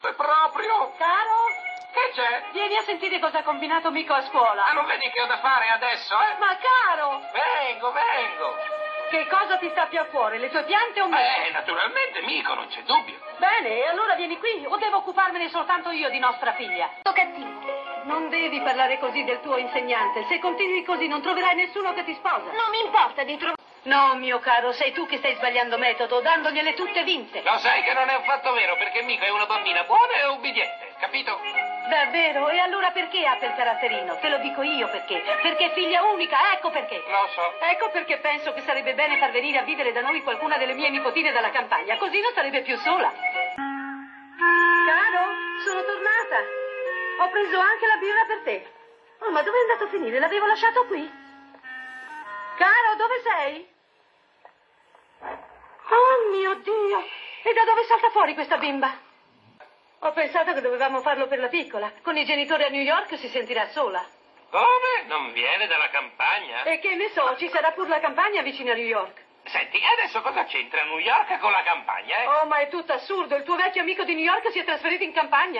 nel cartone animato "Hela Supergirl", in cui doppia la madre.